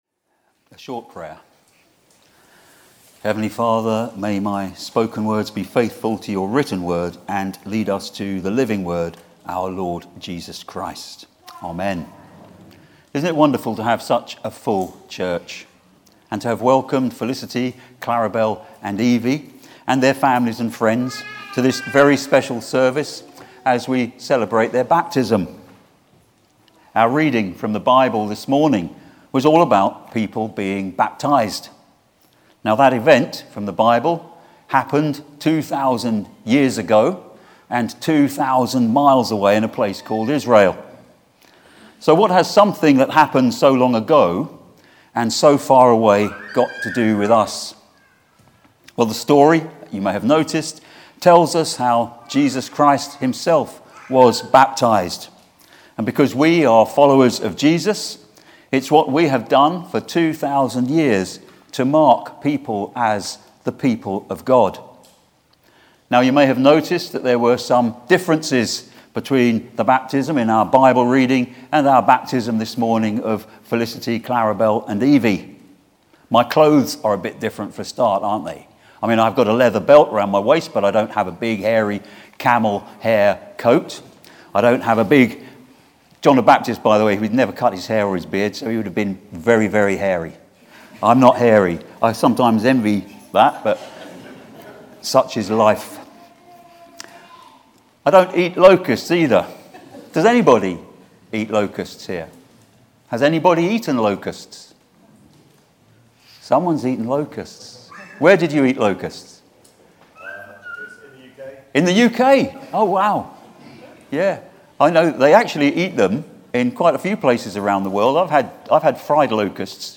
Theme: The Baptism of Jesus Sermon